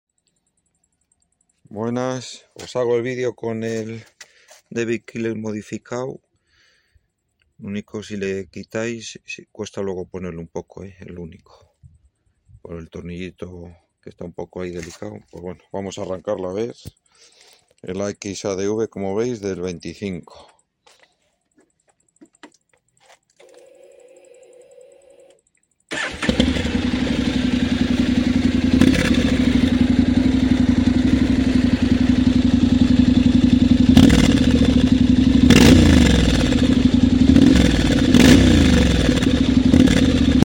⸻ 🔥🏍 Honda XADV 750 + Akrapovic + Premium Modified Dbkiller = Sound That Impresses 🏍🔥 Our customer just sent us this video of their XADV 750 equipped with an Akrapovic exhaust and our Premium Modified Dbkiller. The difference is clear: ⚡ The stock exhaust barely makes any sound ⚡ With our dbkiller, the sound becomes powerful and full of character ⚡ Crackles and street presence multiply This is quality and passion for detail.